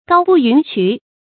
高步云衢 gāo bù yún qú 成语解释 步：行走；衢：大路；云衢：云中大路，比喻显位。